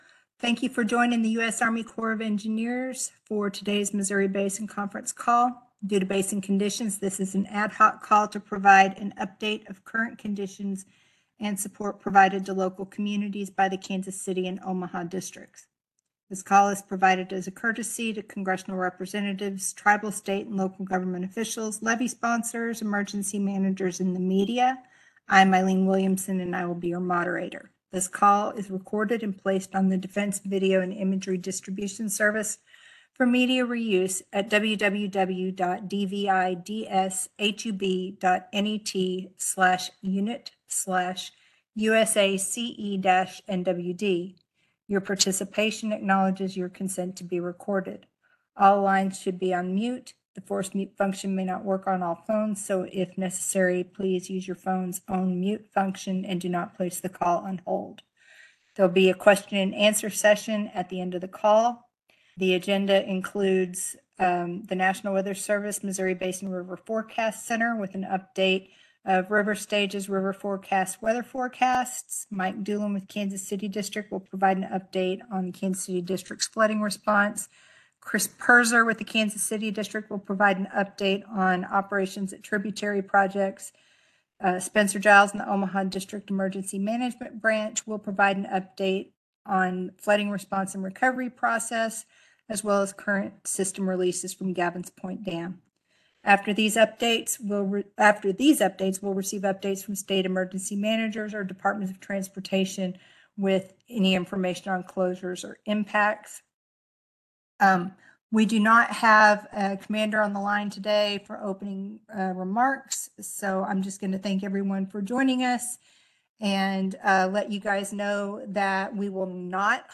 Missouri River Basin Water Management - Missouri River Basin Water Management - Flood Response - Ad hoc Call - 07/06/24